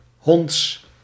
Ääntäminen
IPA : /ɪm.ˈpɜː.tɪ.nənt/